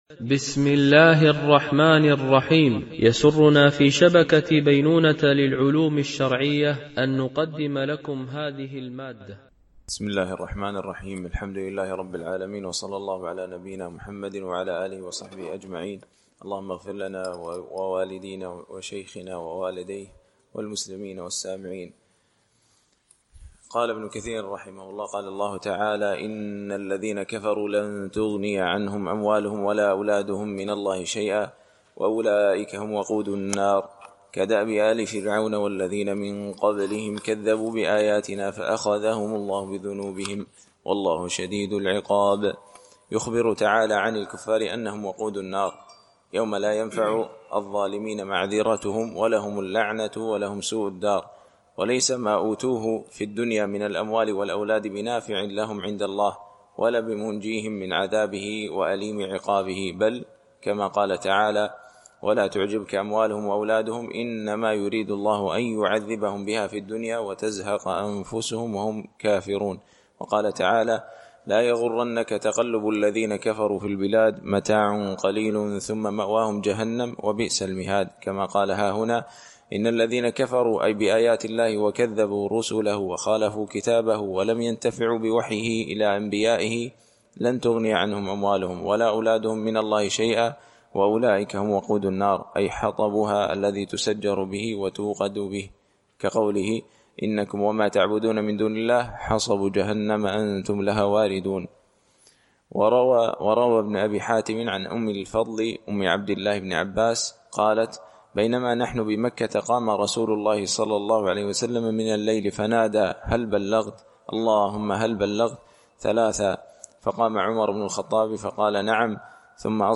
شرح مختصر تفسير ابن كثير (عمدة التفسير) ـ الدرس 104 (سورة آل عمران - الجزء ٢ )